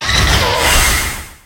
Cri de Melmetal dans Pokémon HOME.